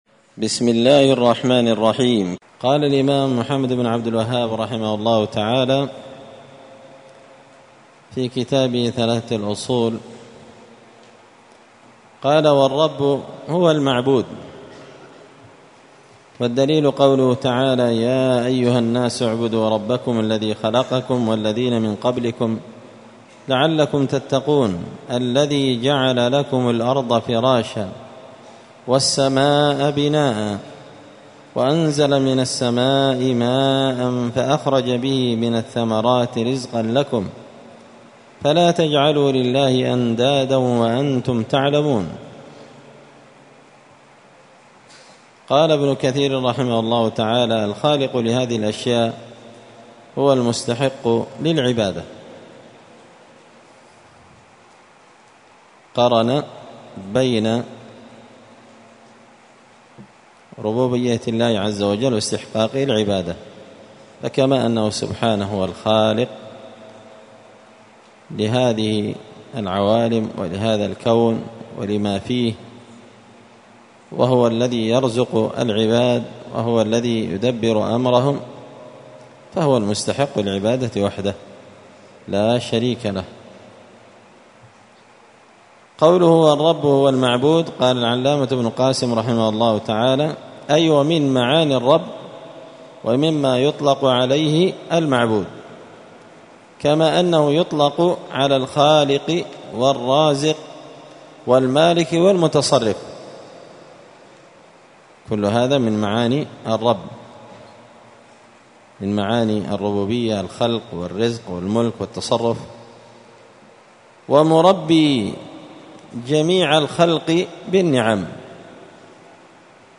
الأربعاء 17 ربيع الثاني 1445 هــــ | الدروس، حاشية الأصول الثلاثة لابن قاسم الحنبلي، دروس التوحيد و العقيدة | شارك بتعليقك | 48 المشاهدات